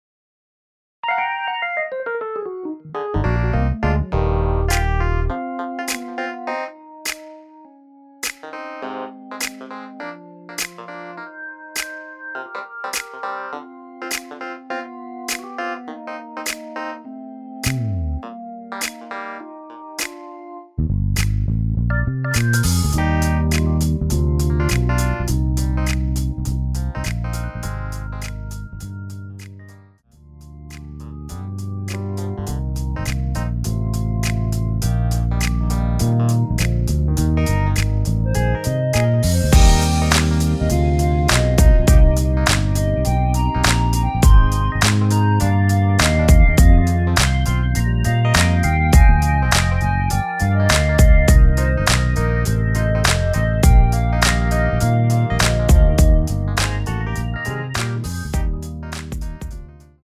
MR은 2번만 하고 노래 하기 편하게 엔딩을 만들었습니다.(본문의 가사와 코러스 MR 미리듣기 확인)
원키에서(+3)올린 멜로디 포함된 MR입니다.
Eb
앞부분30초, 뒷부분30초씩 편집해서 올려 드리고 있습니다.
중간에 음이 끈어지고 다시 나오는 이유는